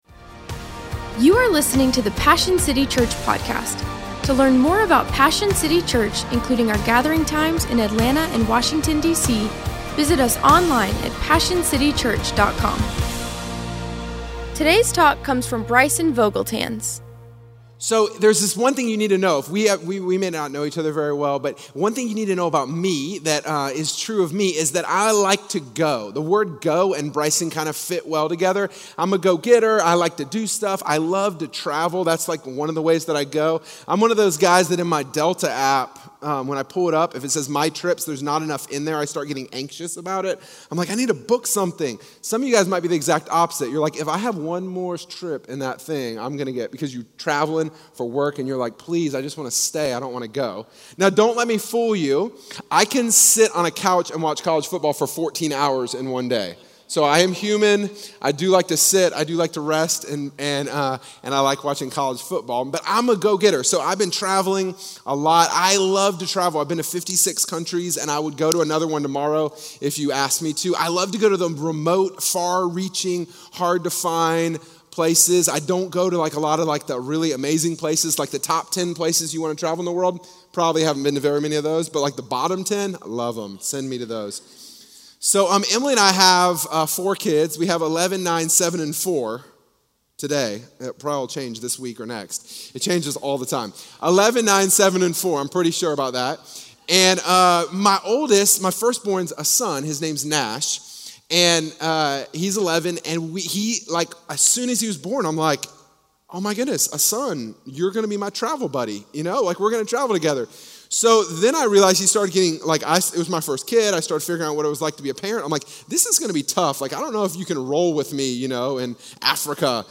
Christianity, Passion, Religion & Spirituality, Passionconferences, Messages, Louiegiglio, Sermons, Passioncitychurch, Church